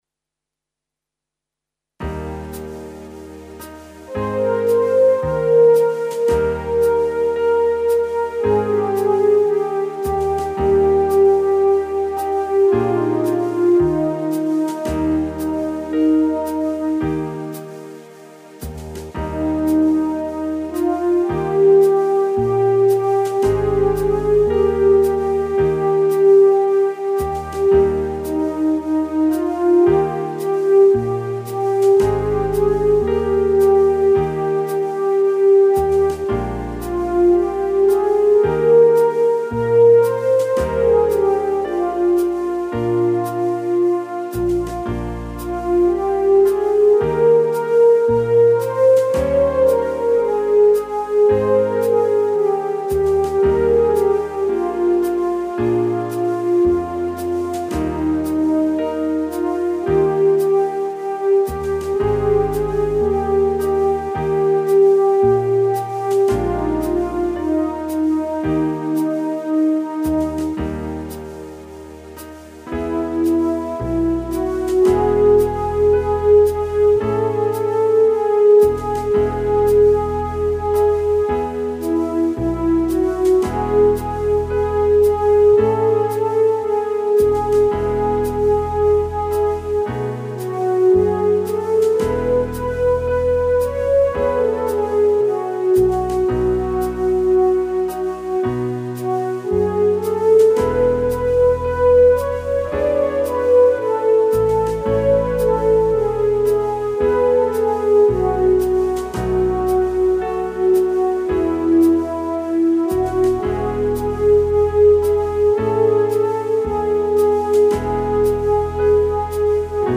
Mota: Gabon Kanta Musika: Amerikar Herrikoia Testuak